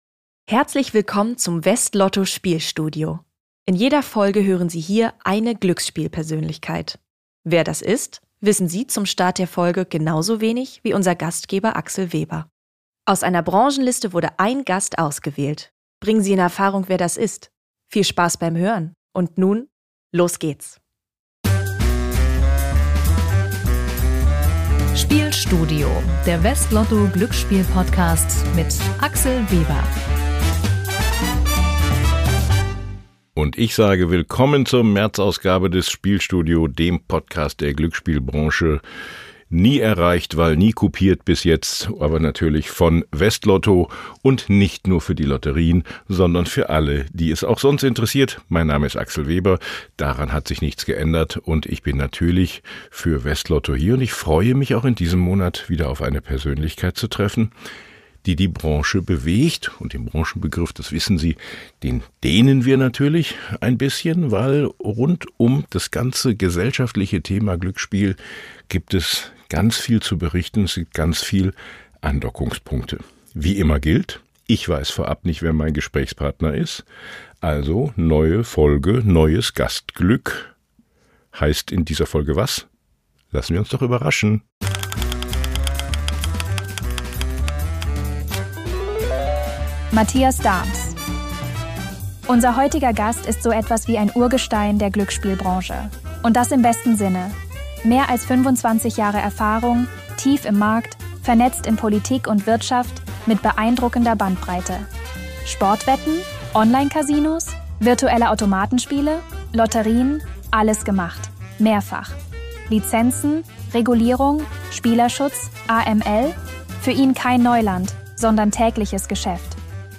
Hinweis: Ein Zuspieler in diesem Podcast wurde mit einer künstlichen Stimme erzeugt, die von einem KI-System erstellt wurde.